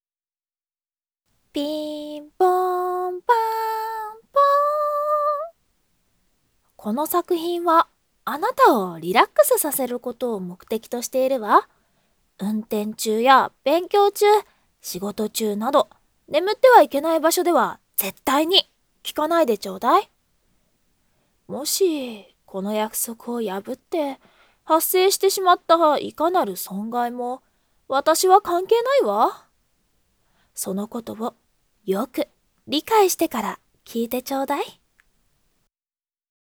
【110円】魔女様の催眠魔法～日々に疲れたあなたに精一杯を癒しを～【囁き安眠】
催眠音声